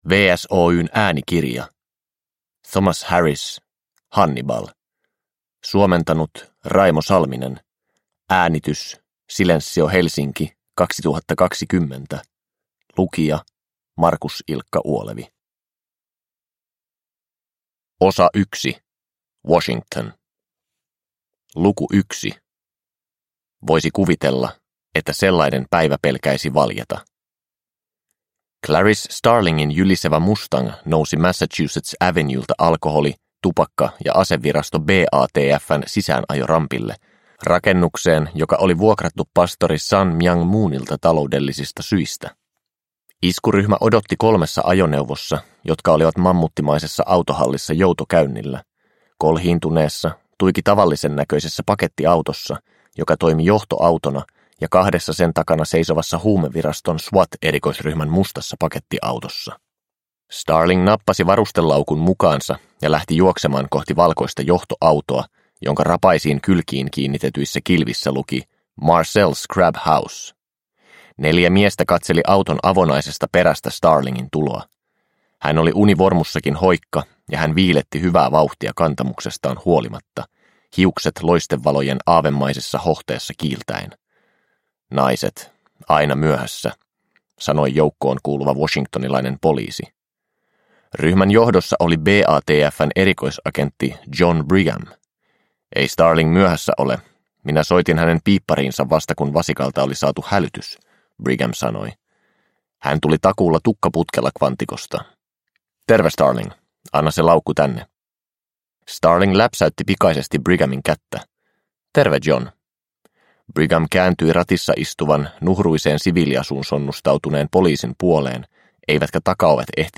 Hannibal – Ljudbok – Laddas ner